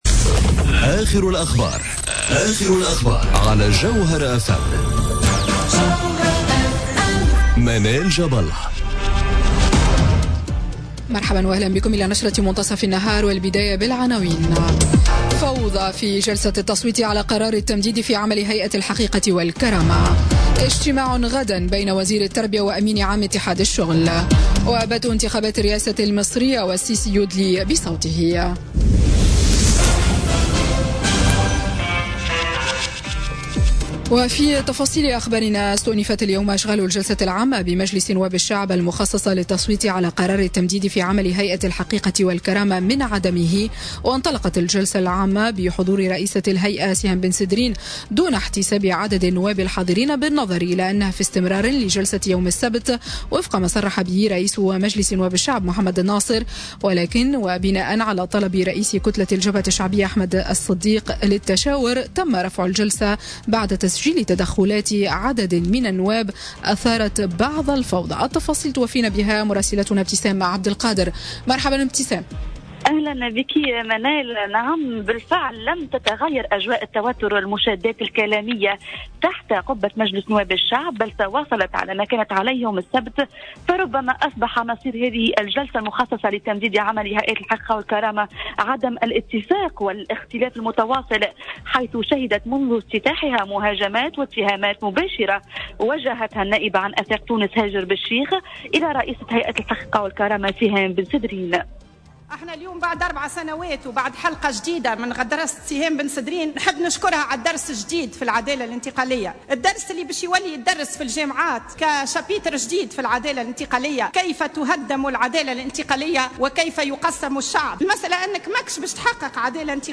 نشرة أخبار منتصف النهار ليوم الإثنين 26 مارس 2018